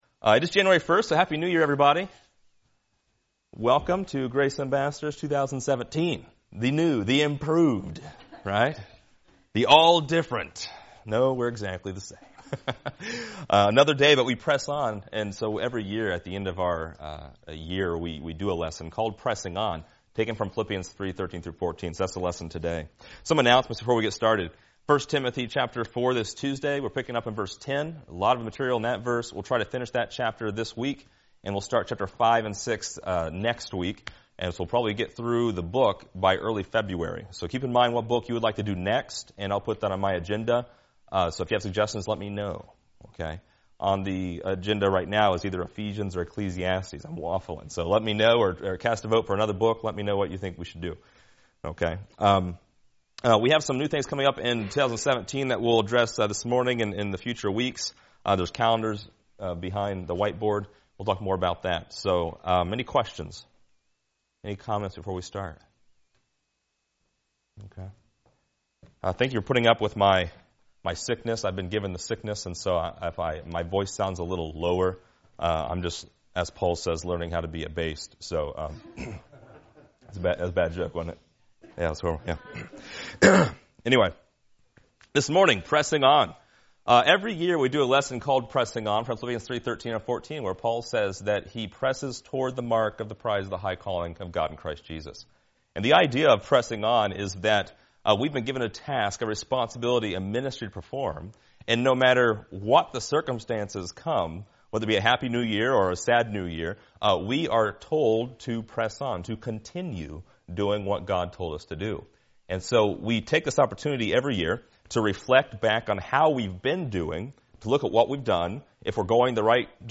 Description: Annually, we reflect on the past year’s growth at Grace Ambassadors Bible Fellowship. This year’s “Pressing On” lesson continues our tradition of reflection while looking ahead at our goals for 2017.